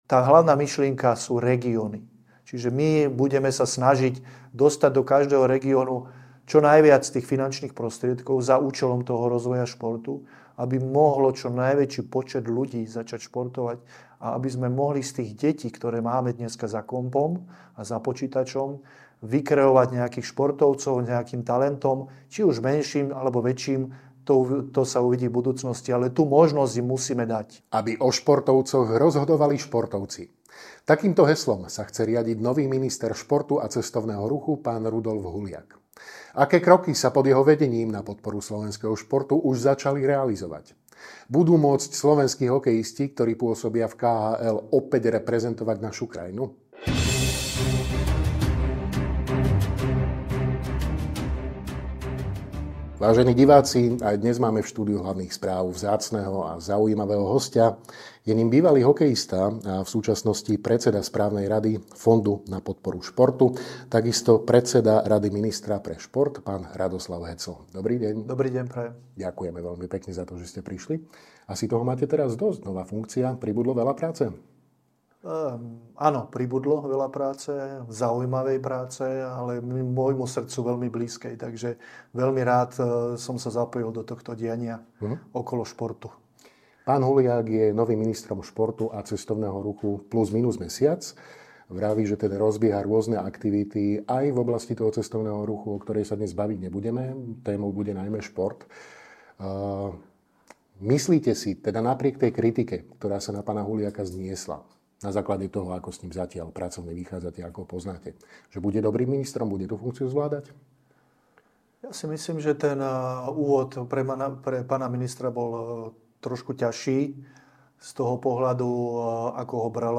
Rozprávali sme sa s bývalým hokejovým obrancom a súčasným predsedom Fondu na podporu športu, ako aj šéfom Rady ministra pre šport, Radoslavom Heclom.